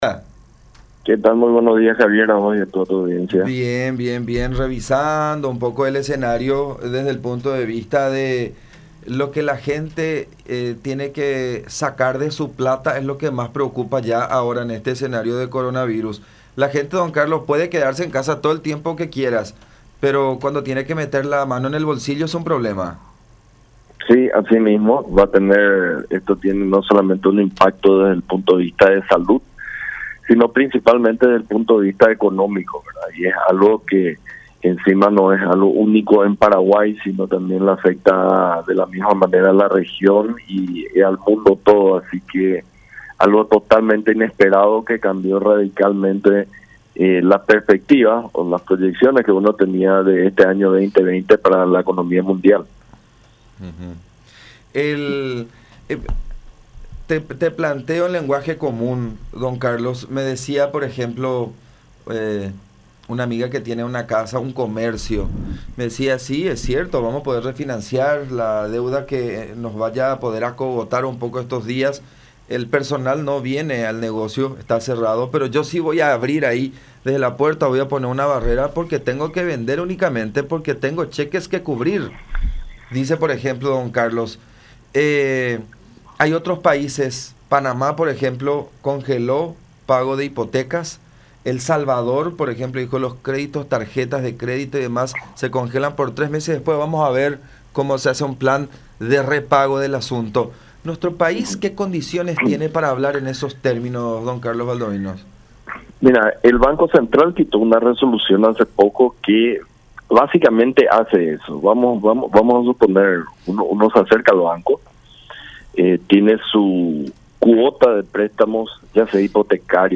Carlos Fernández Valdovinos, extitular de Banco Central del Paraguay (BCP).
“Se debe felicitar al gobierno porque aceleró las medidas de salud, mantenemos solo 11 casos de coronavirus”, dijo Fernández Valdovinos en diálogo con La Unión.